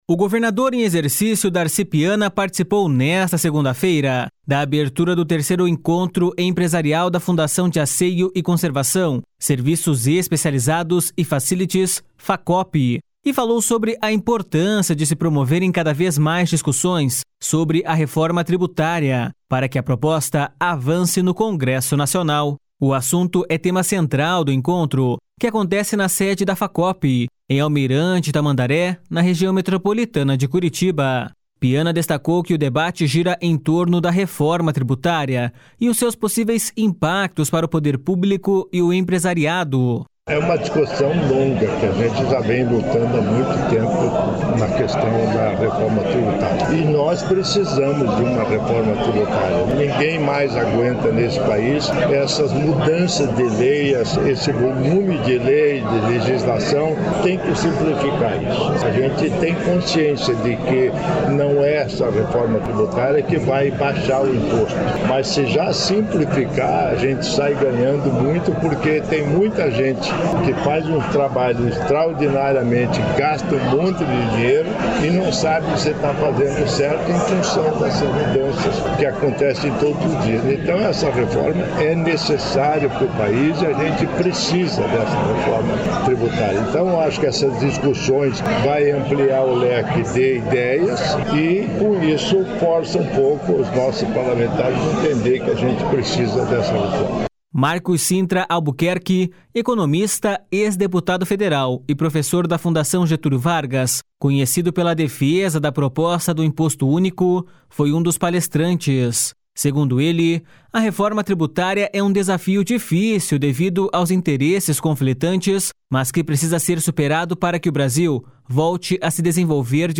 Piana destacou que o debate gira em torno da reforma tributária e os seus possíveis impactos para o poder público e o empresariado.// SONORA DARCI PIANA.//
Segundo ele, a reforma tributária é um desafio difícil devido aos interesses conflitantes, mas que precisa ser superado para que o Brasil volte a se desenvolver de forma sustentável.// SONORA MARCOS CINTRA ALBUQUERQUE.//